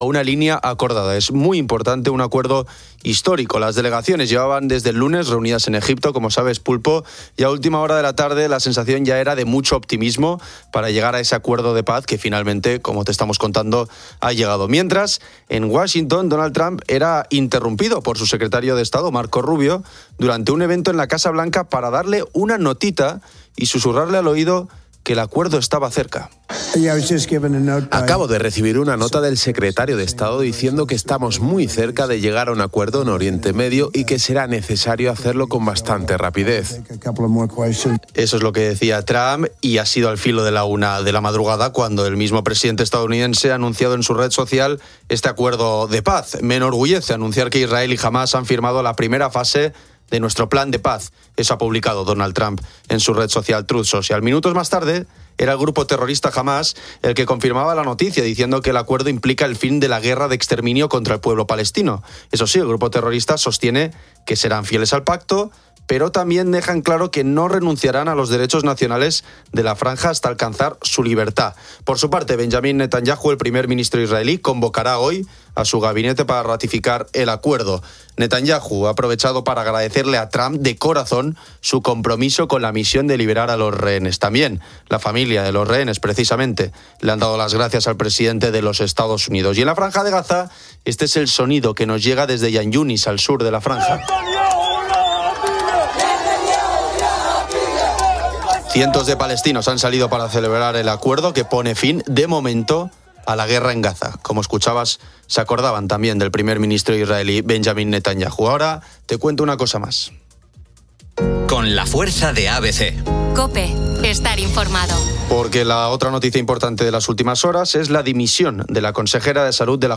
Los oyentes de COPE debaten sobre finanzas personales y gestión del ahorro, expresando preocupación por un posible nuevo apagón en España. Grupo Risa realiza una broma telefónica a una oyente de Zaragoza.